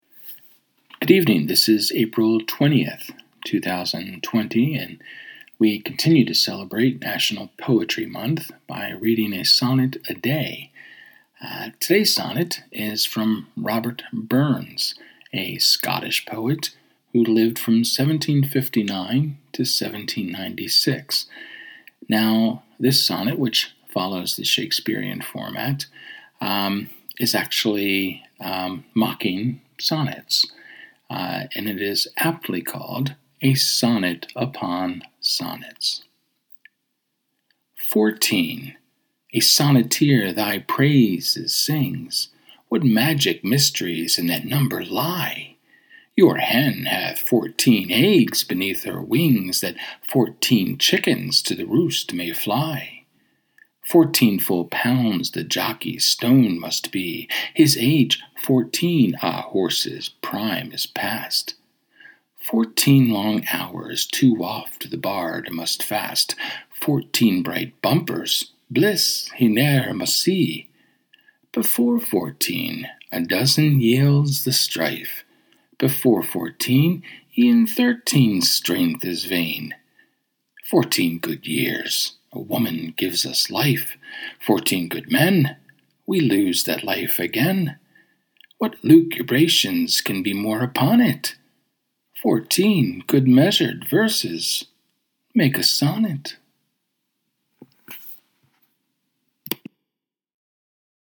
Welcome to Day 20 of our daily sonnets celebrating National Poetry Month. Tonight, I am reading Robert Burns’ “A Sonnet Upon Sonnets,” which he wrote in the Shakespearean sonnet form.